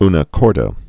(nə kôrdə)